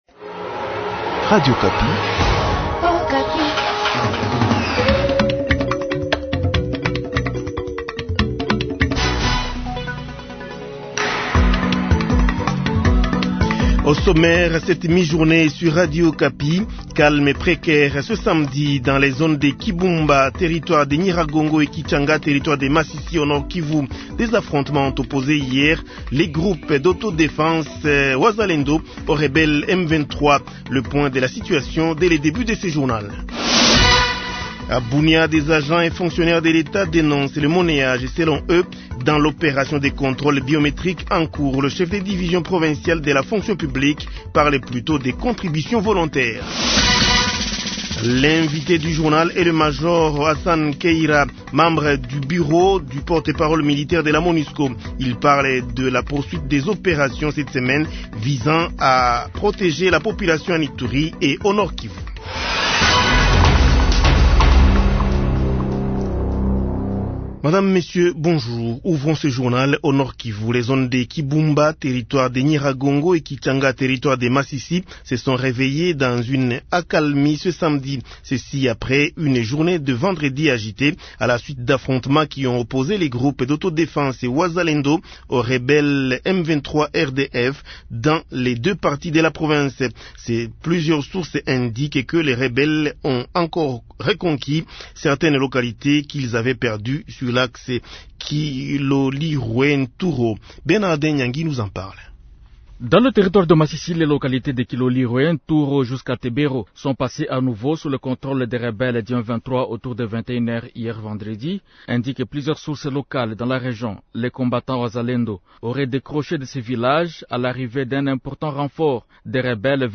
Journal Midi Français Samedi 7 Octobre 2023